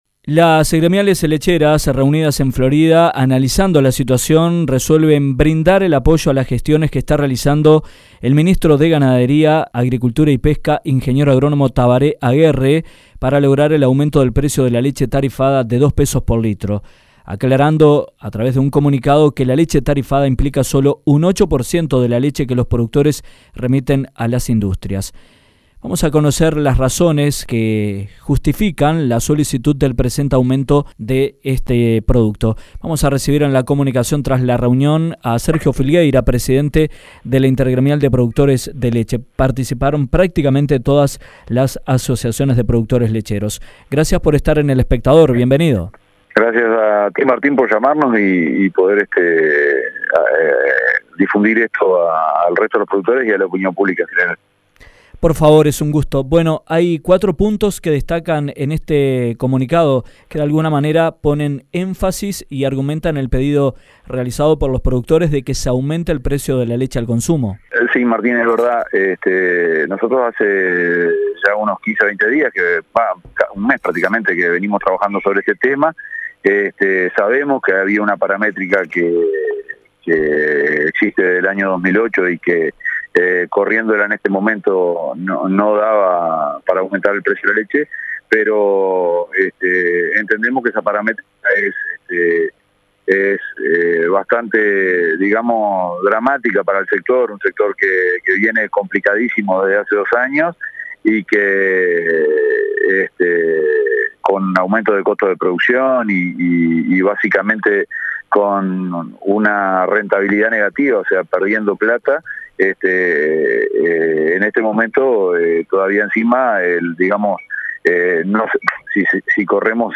En entrevista con Dinámica Rural